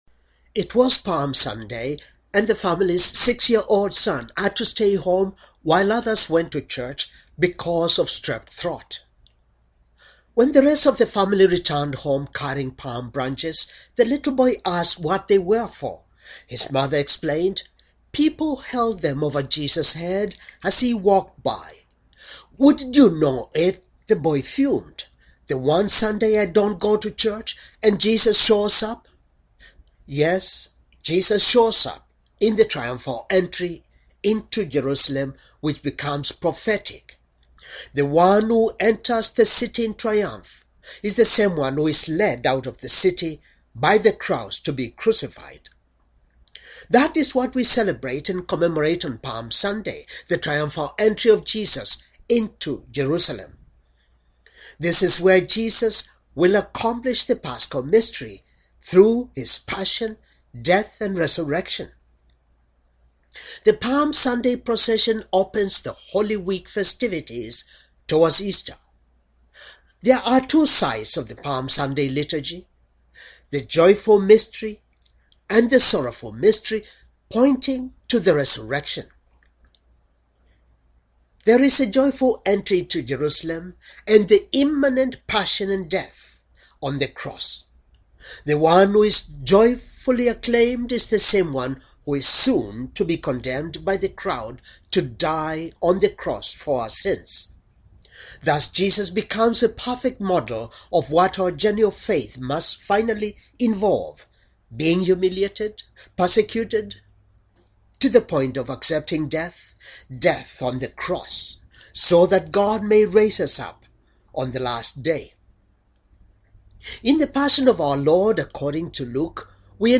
Homily for Palm Sunday